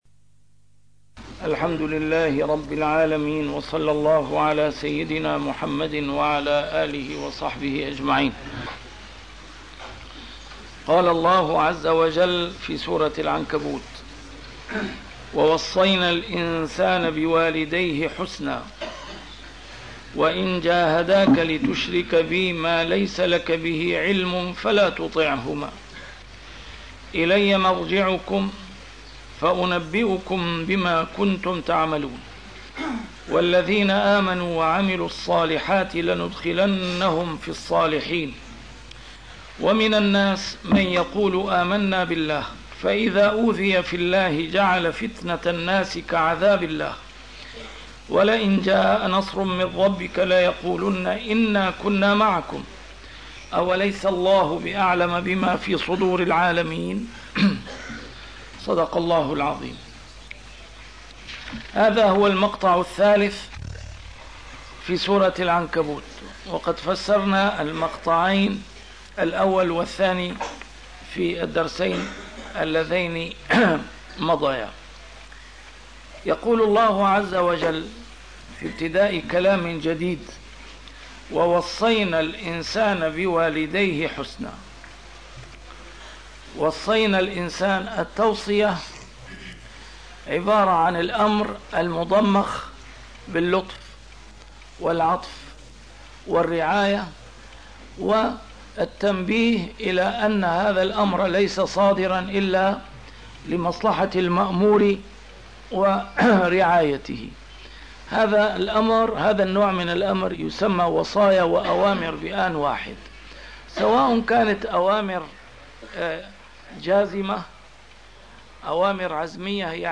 A MARTYR SCHOLAR: IMAM MUHAMMAD SAEED RAMADAN AL-BOUTI - الدروس العلمية - تفسير القرآن الكريم - تسجيل قديم - الدرس 292: العنكبوت 08-09